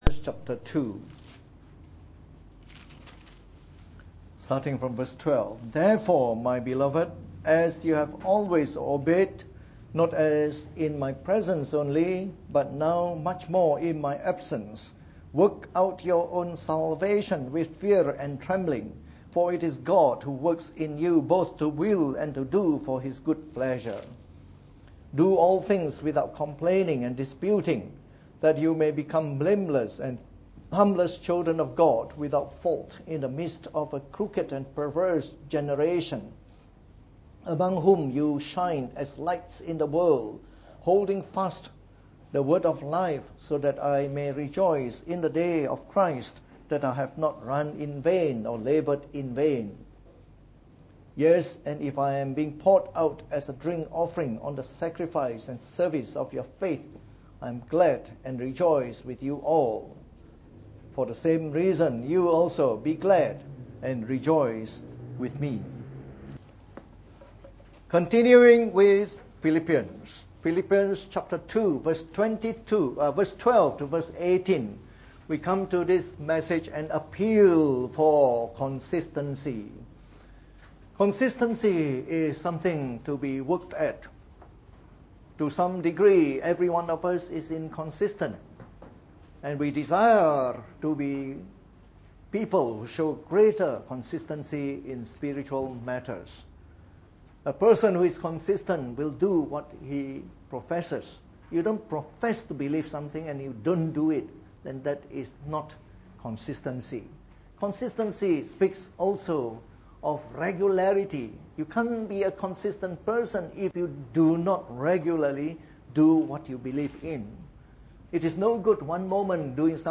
From our series on the Epistle to the Philippians delivered in the Morning Service.